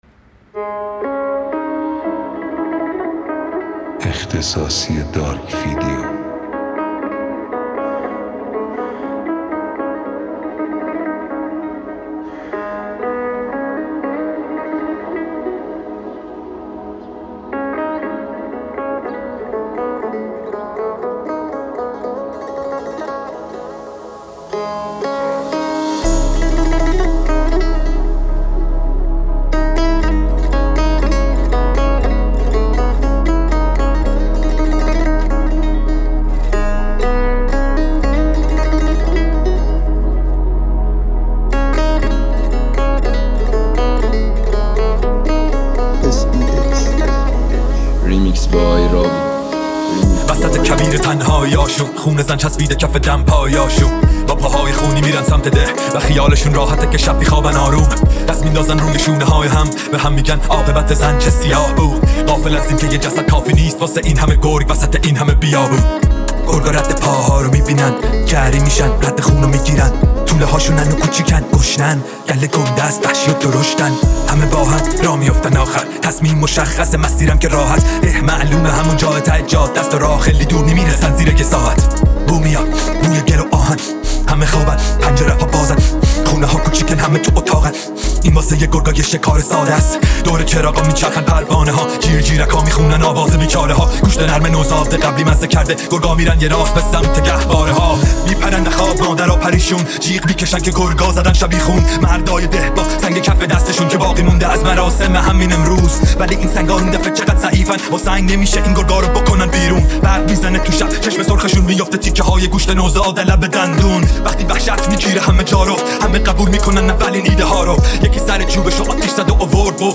ریمیکس ترکیبی